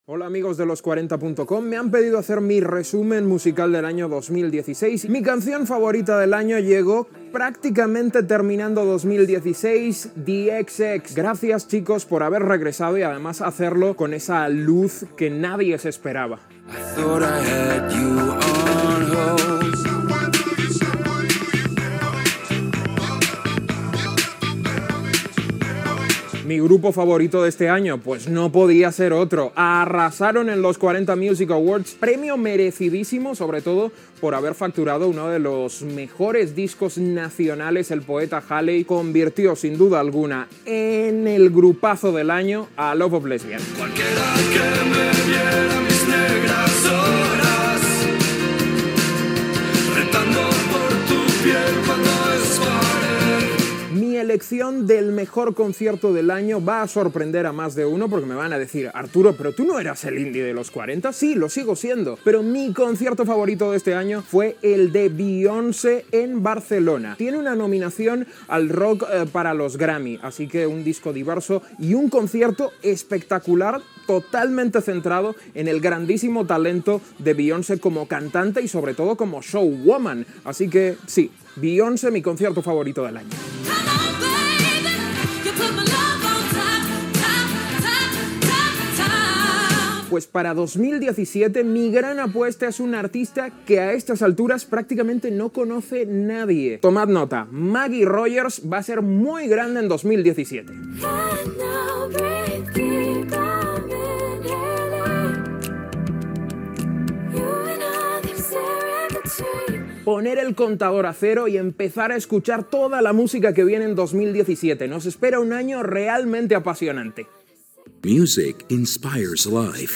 Millors artistes del 2016 Gènere radiofònic Musical